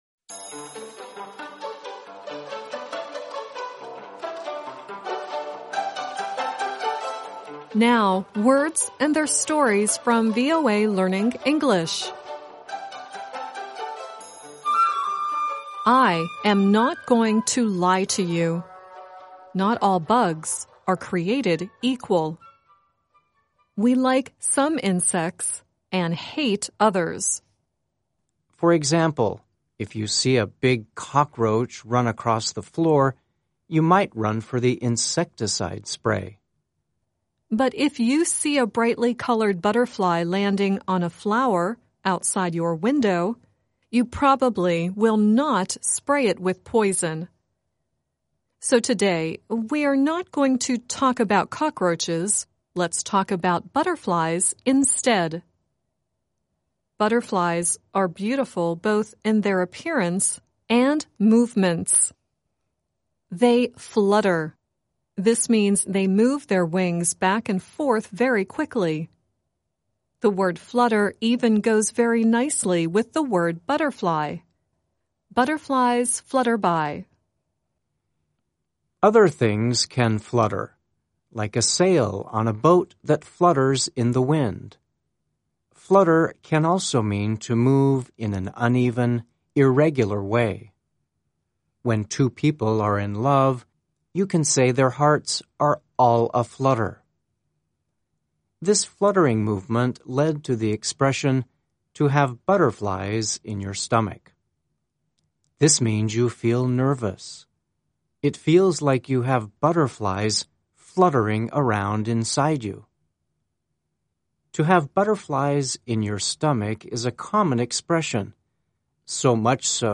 The song at the end is Diana Ross singing “Chain Reaction.”